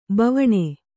♪ bavaṇe